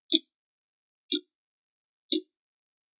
tick.wav